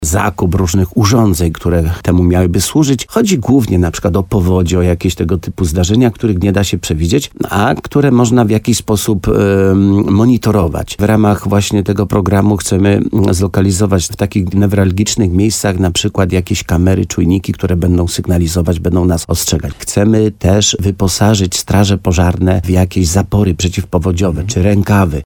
Jak zaznaczył w programie Słowo za Słowo wójt Leszek Skowron, w przypadku gminy Korzenna chodzi przede wszystkim o możliwość przeciwdziałania gwałtownym burzom i innym zjawiskom pogodowym.